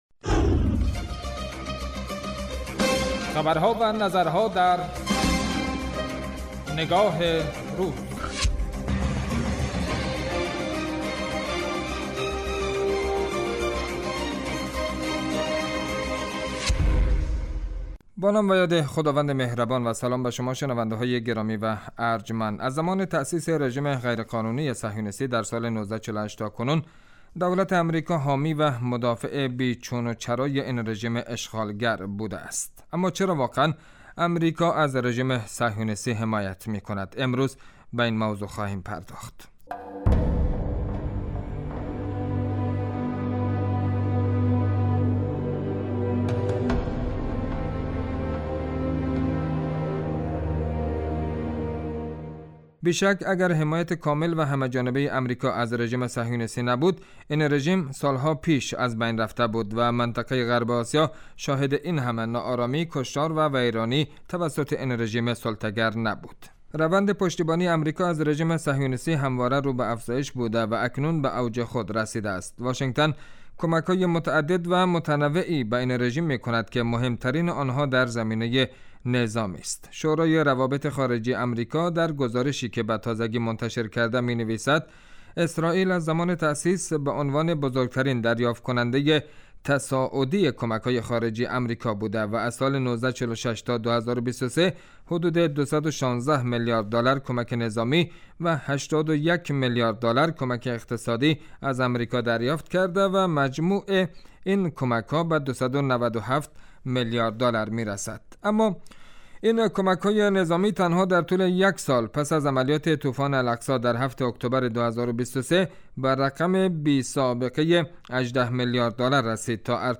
رادیو